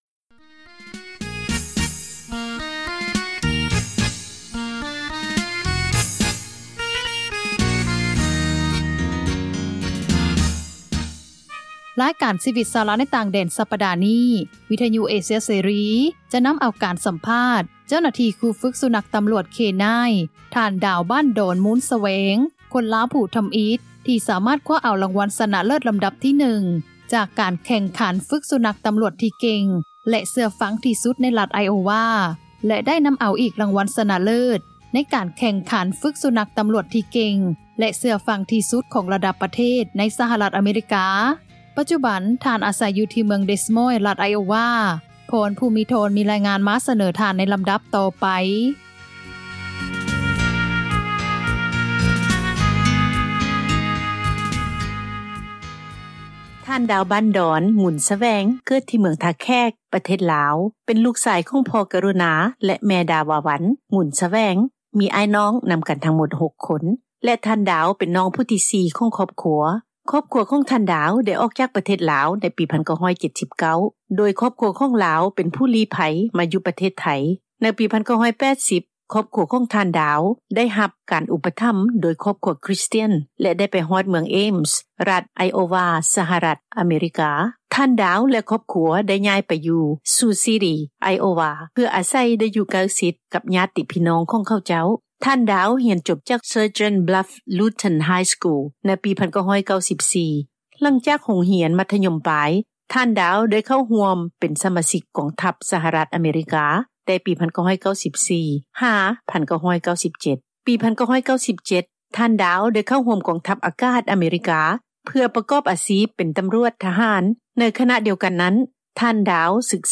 ຣາຍການຊີວິດ ຊາວລາວໃນຕ່າງແດນ ສັປດານີ້, ວິທຍຸເອເຊັຽເສຣີ ຈະນໍາເອົາການສັມພາດ ເຈົ້າໜ້າທີ່ ຄຣູຝຶກສຸນັກຕໍາຣວດ ເຄນາຍນ໌ (K9 Officer)